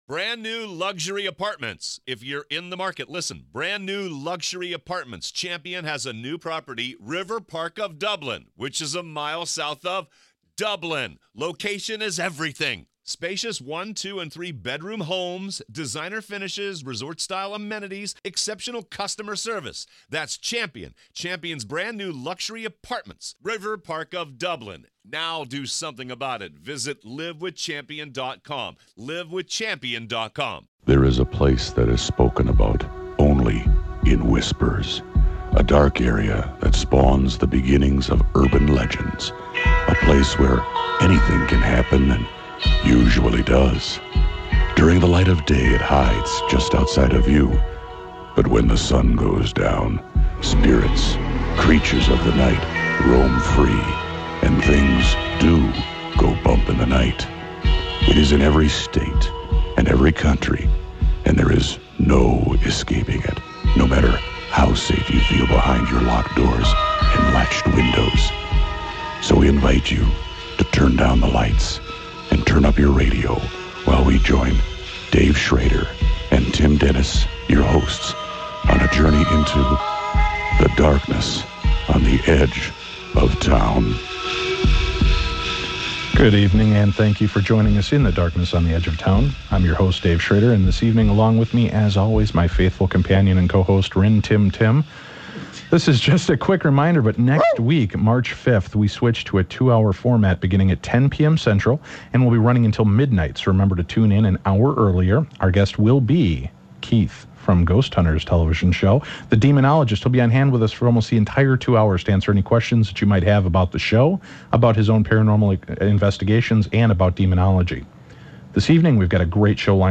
joins Darkness Radio to take calls from our audience and give mini psychic readings...